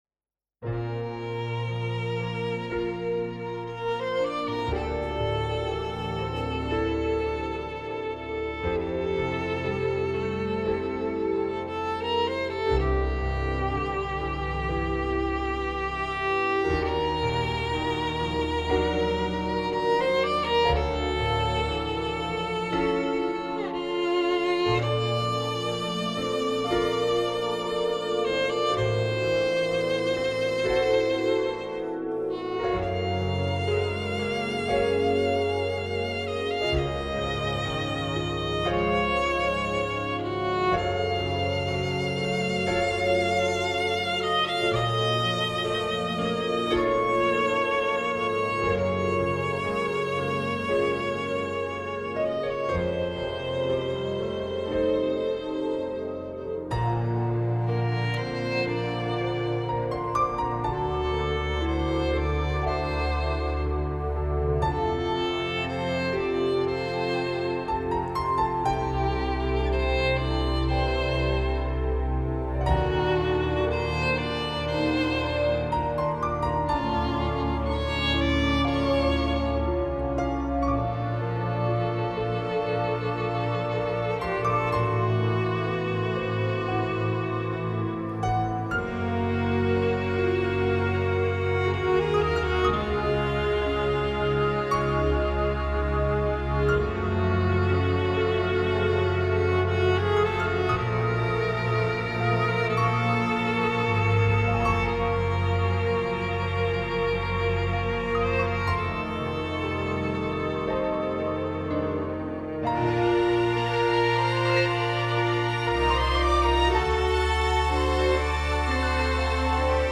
Le voci di questi file audio sono autoprodotte e da intendersi come semplice demo per capire le linee melodiche